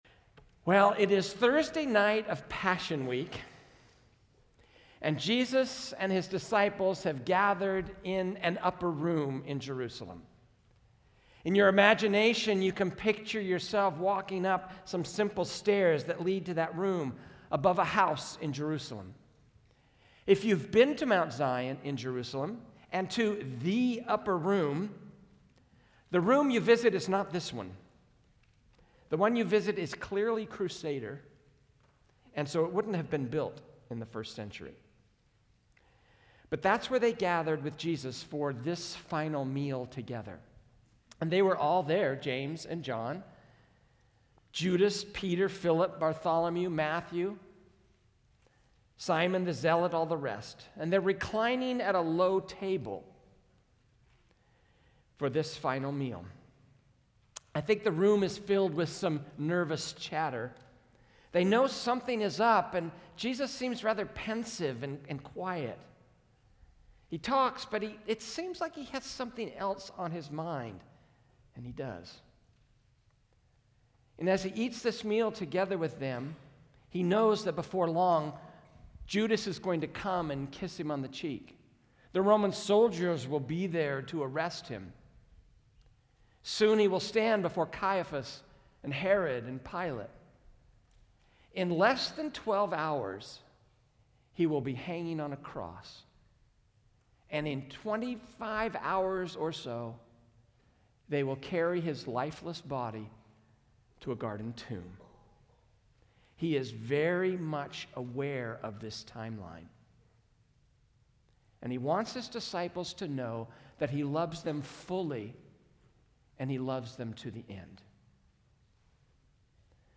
A message from the series "The Farewell." The resurrection of Jesus Christ profoundly changed the lives of those who followed Him and it can transform our lives as well.